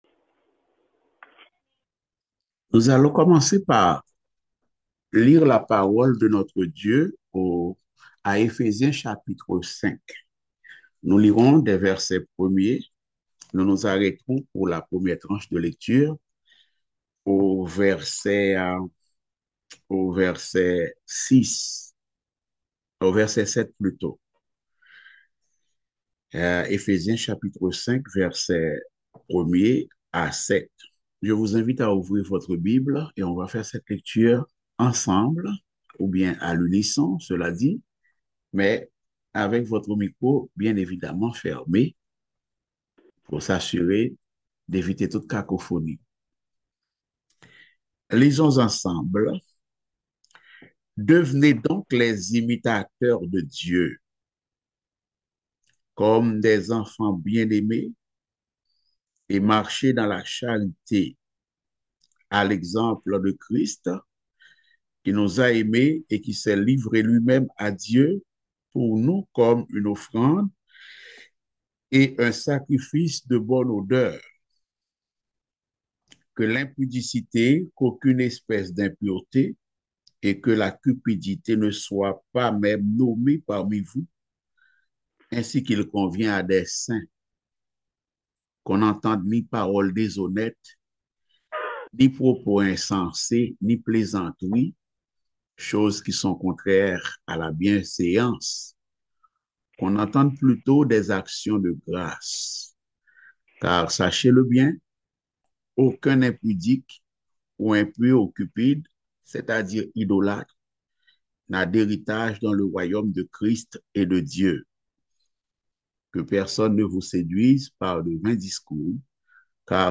Passage: Éphésiens 5.1-7 Type De Service: Études Bibliques « Les éternels bienfaits de Dieu.